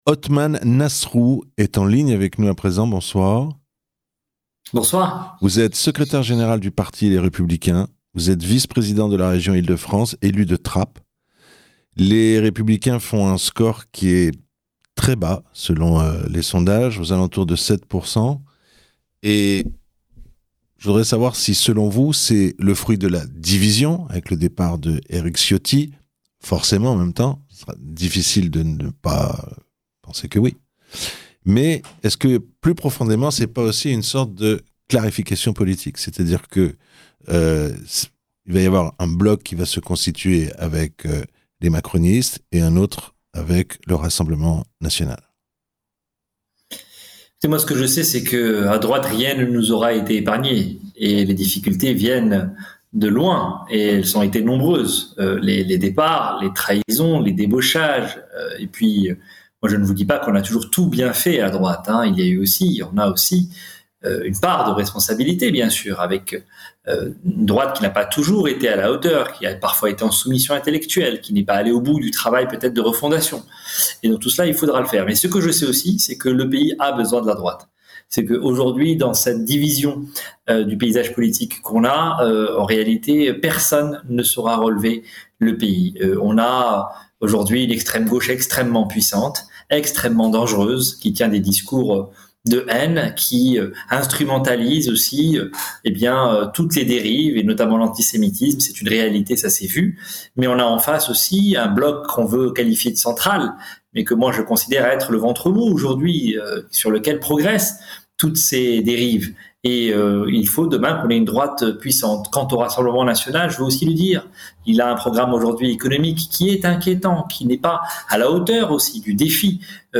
Othman Nasrou, Secrétaire Général des LR était l'invité du journal de 18h00.